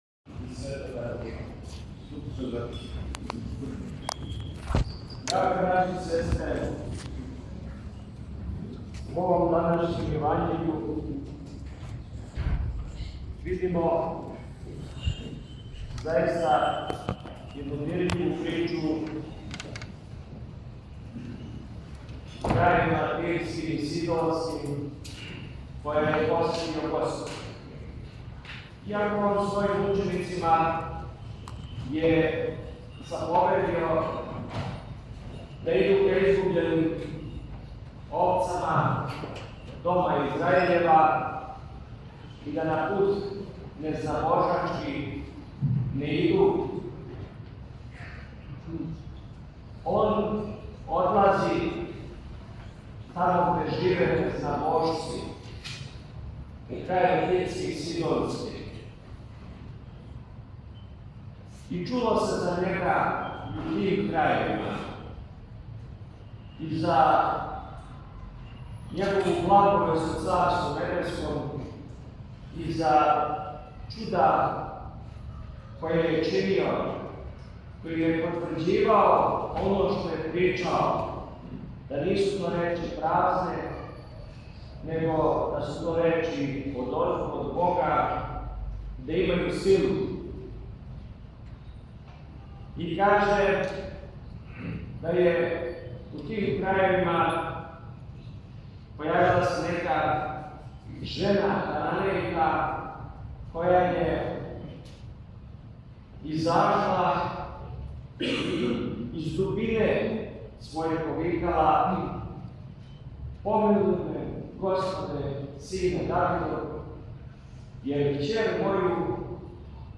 Епископ Доситеј богослужио у храму Светог Георгија у Старој Бежанији
Његово Преосвештенство Епископ липљански г. Доситеј, викар Патријарха српског, служио је свету Литургију у недељу, 1. октобра 2023. године, у храму светог Георгија у београдском насељу Бежанија. Звучни запис беседе